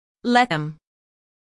let-them-us-female.mp3